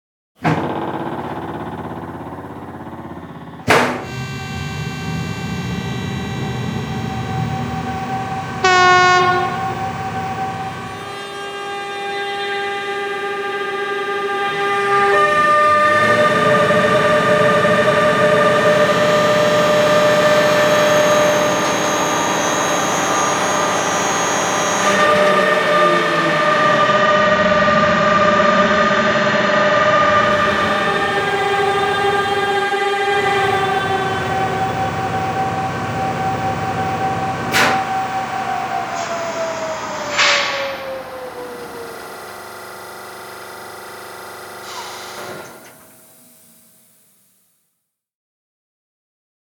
Märklin 38800 Demo-Sound.mp3